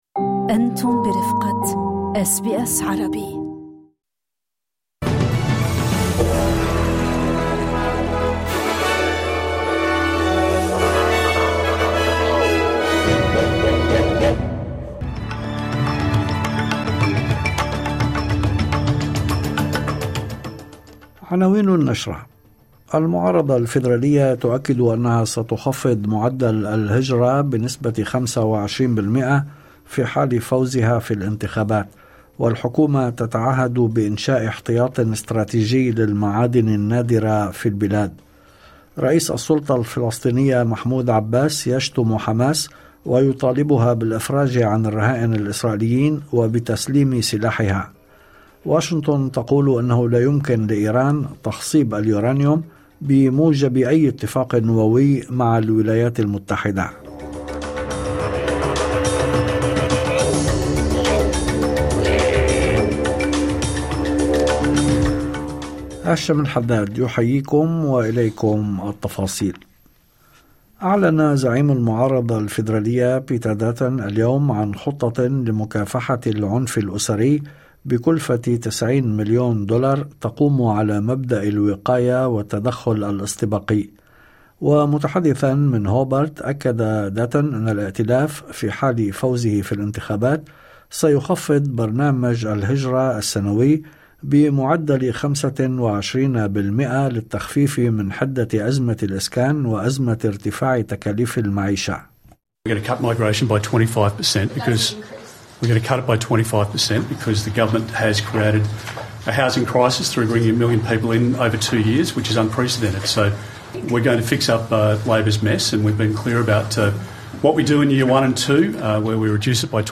نشرة أخبار المساء 24/04/2025